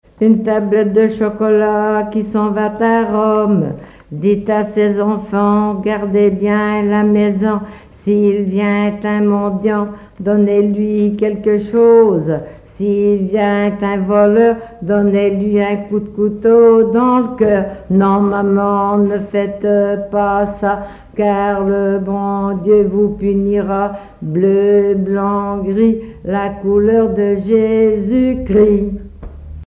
rondes enfantines
collecte en Vendée
répertoire enfantin
Pièce musicale inédite